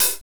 HAT H H LH0C.wav